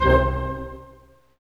Index of /90_sSampleCDs/Roland LCDP08 Symphony Orchestra/HIT_Dynamic Orch/HIT_Staccato Oct